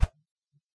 Weapon_switch.ogg